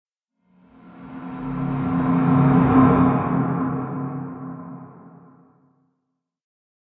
Звуки пещер из Майнкрафт
Находясь в пещерах Майнкрафт можно услышать множество разных тревожных и порой даже пугающих звуков.